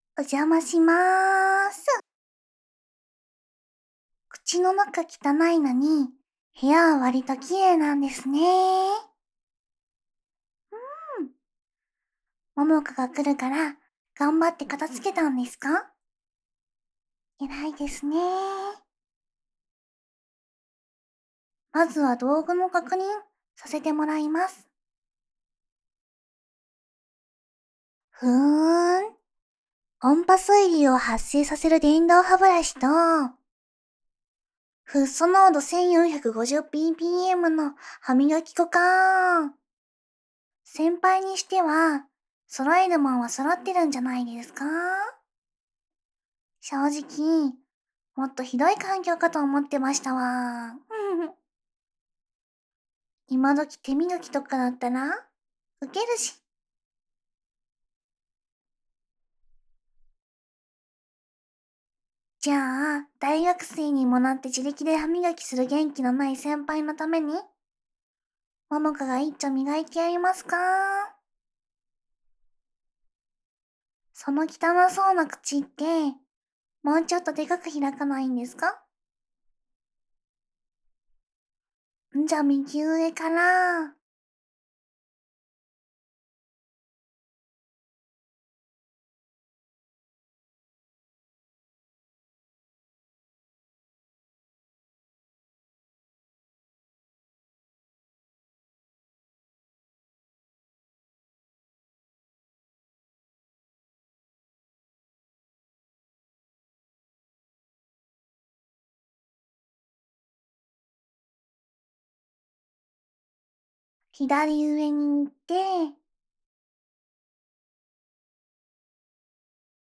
ASMR
ももかの歯磨き.wav